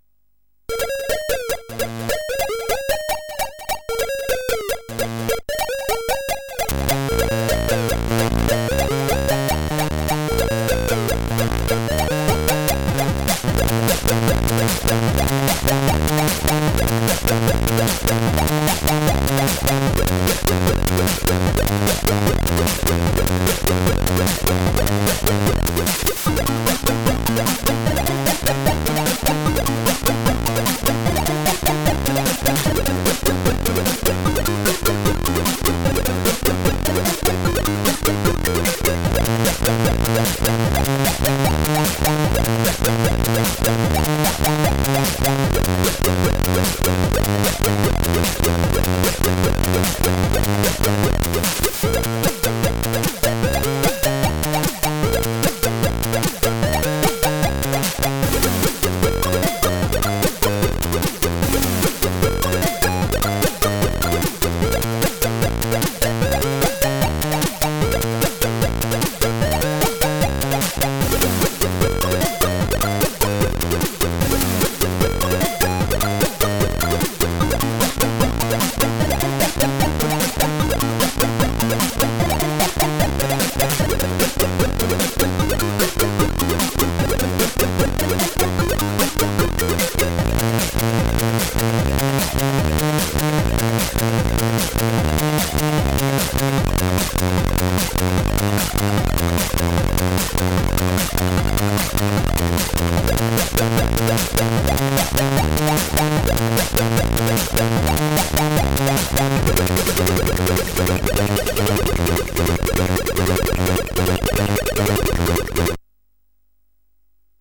AY Music Compo